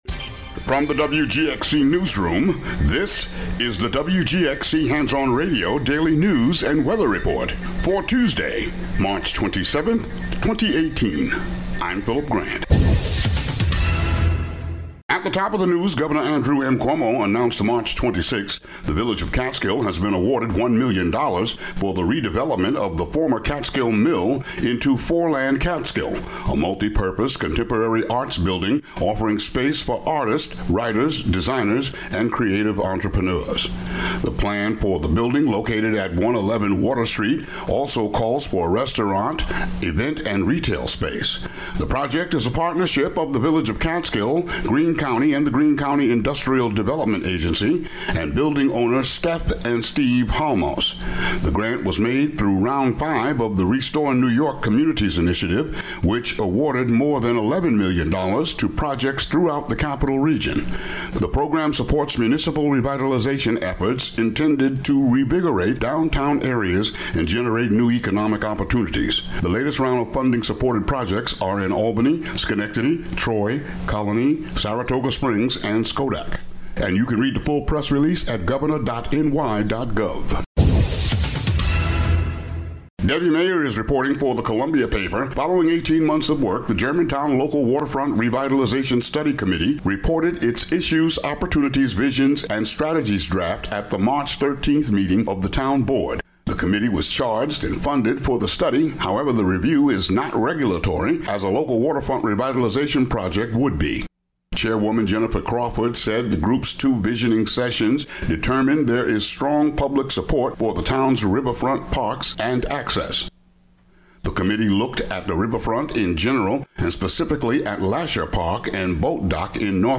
Weekend edition of the local news for WGXC.